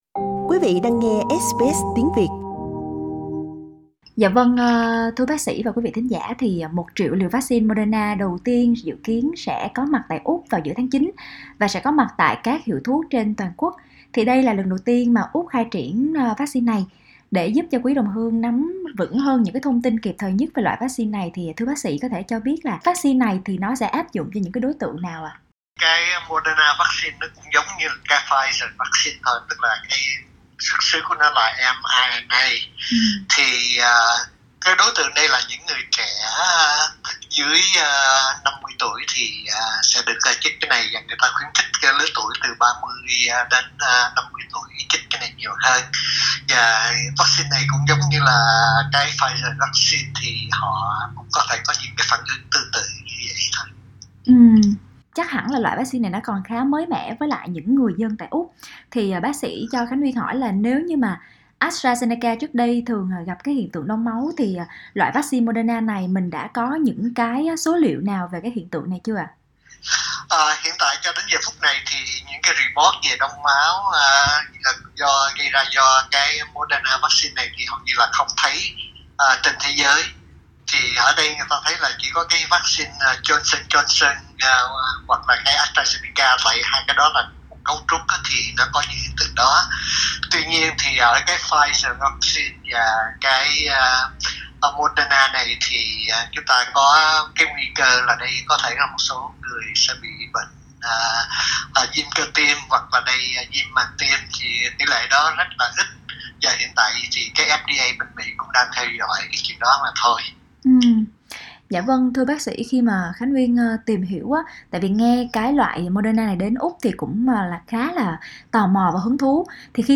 trò chuyện với SBS Việt Ngữ về vấn đề này rõ hơn trong phần âm thanh phía trên